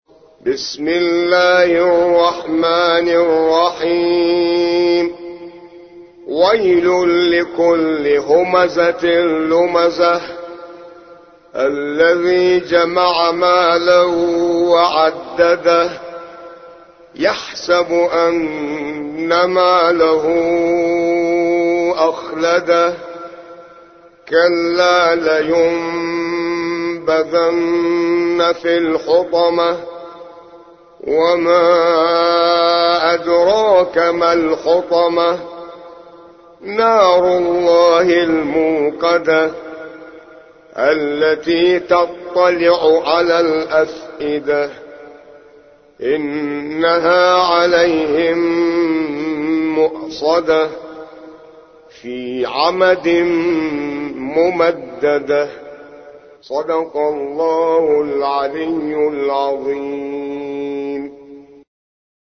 104. سورة الهمزة / القارئ